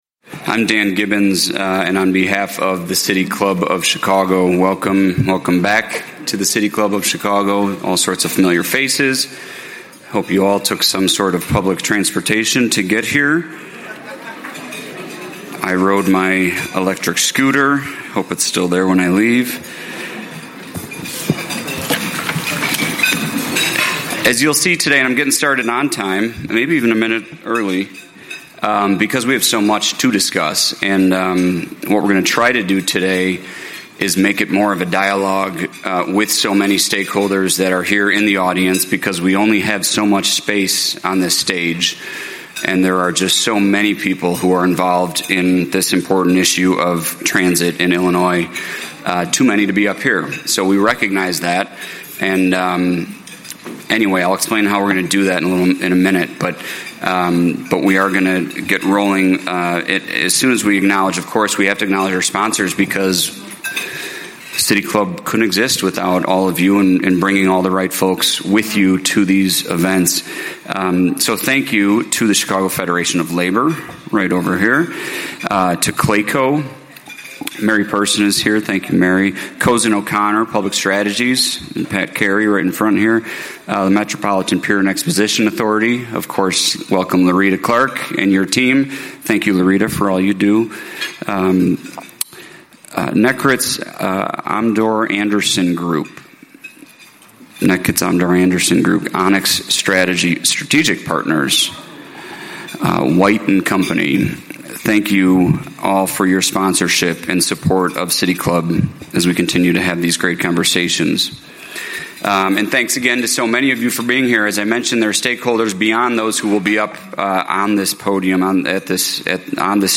Join leading voices for a candid discussion on what’s next for funding, governance, and the riders who depend on these systems every day.
Speakers Donald P. DeWitte Donald P. DeWitte is an Illinois State Senator for the 33rd Senate District.